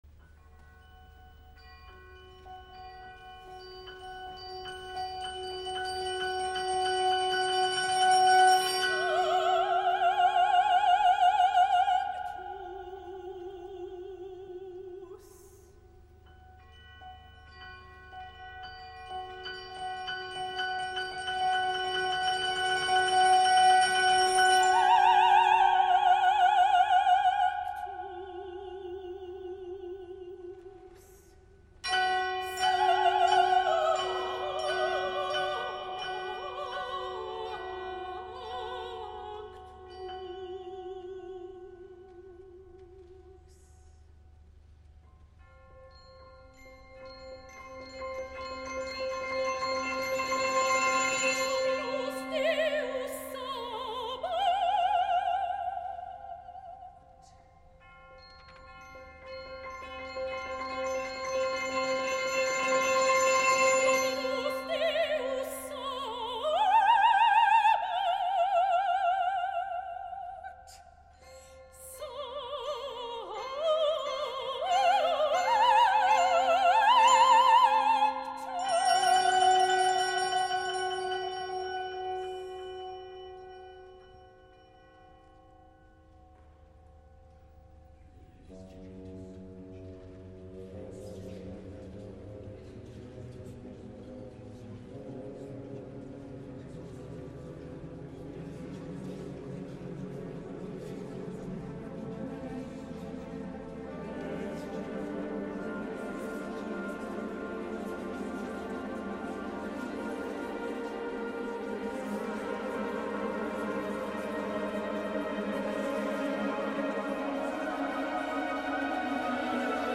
(soprano solo and chorus)
(baritone solo)
Catedral nde Coventry 30/05/2012
en commemoració del 50è aniversari de l’estrena
amb la soprano acompanyada del cor